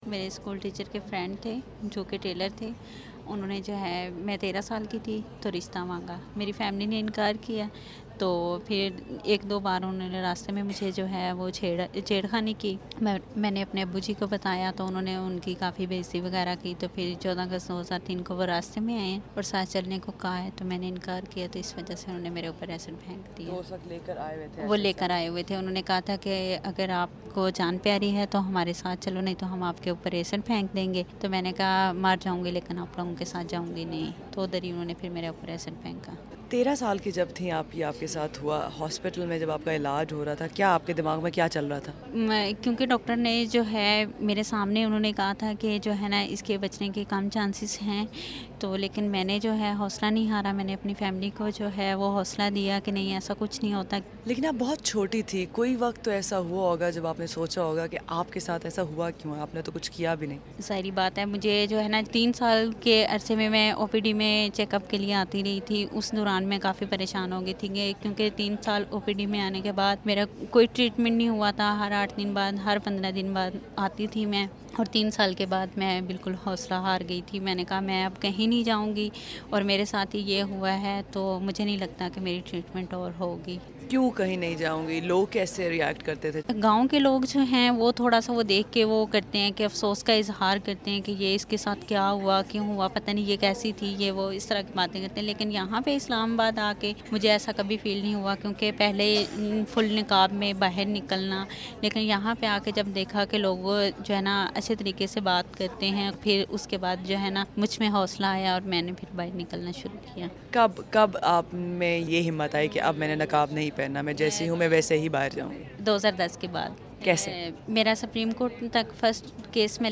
دلیرانہ انٹرویو